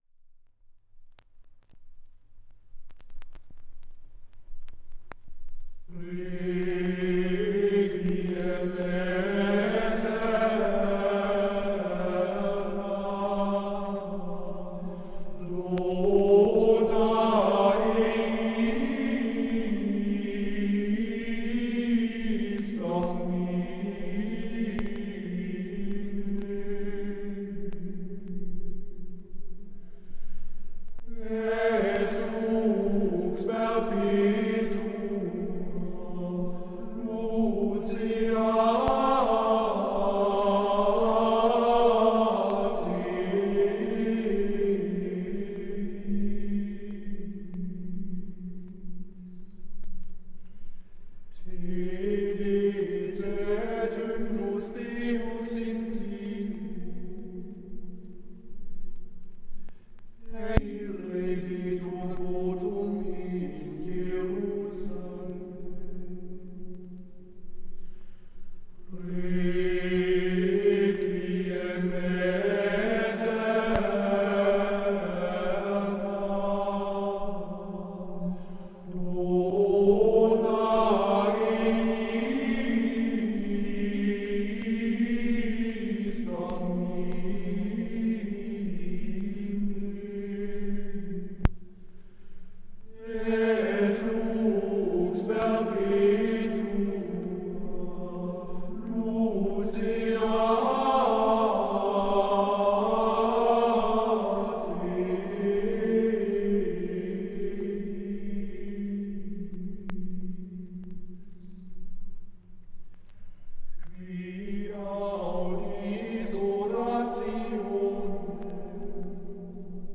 Gregorianischen Chorals
Gesang: Schola gregoriana
aufgenommen in der Klosterkirche Knechtsteden